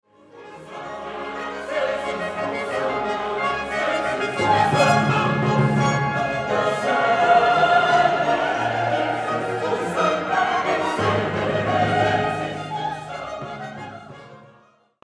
Choir singing
Maria oratoriekör
Sankta Maria Church